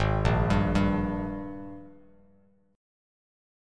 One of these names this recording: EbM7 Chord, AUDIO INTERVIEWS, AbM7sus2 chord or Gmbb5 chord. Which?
AbM7sus2 chord